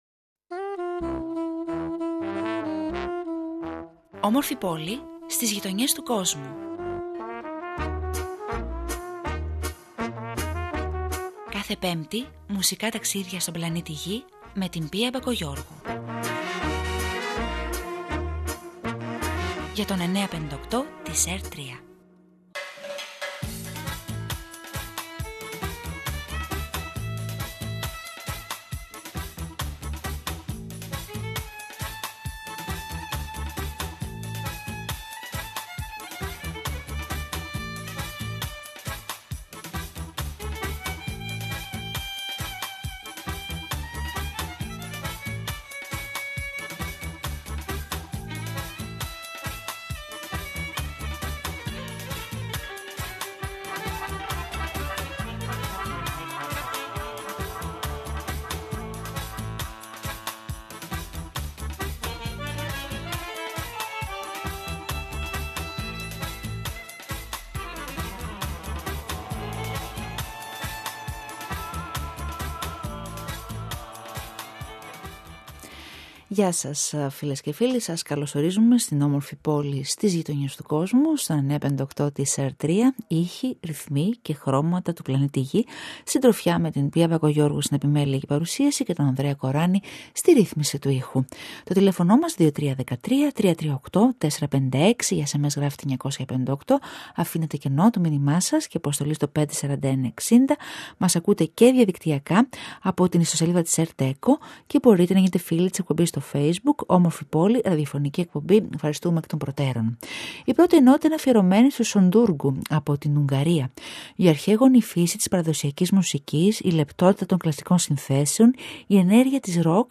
Η πρώτη ενότητα είναι αφιερωμένη στους Söndörgő. Το σχήμα από την Ουγγαρία συνδυάζει άψογα τον σεβασμό για τις παραδόσεις, με την επιθυμία για καινοτομία. Σήμα κατατεθέν τους είναι το μουσικό όργανο tambura.